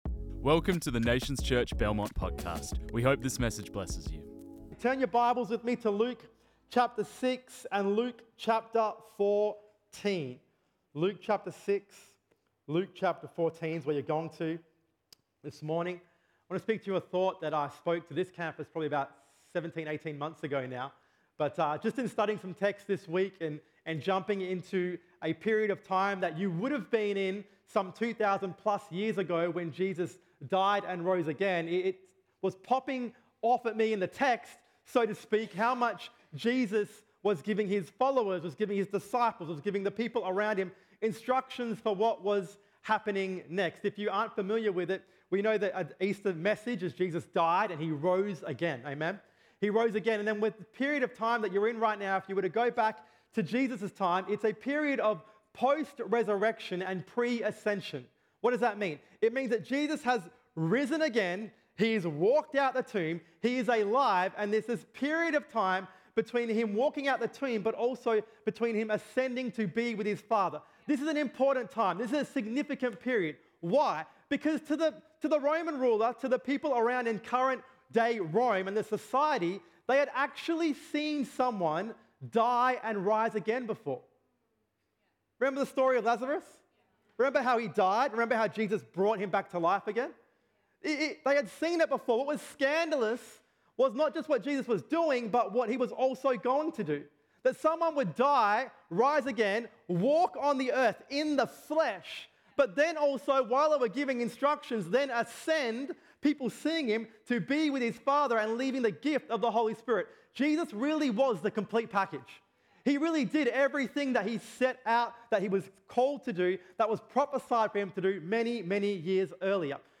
This message was preached on 14 April 2024.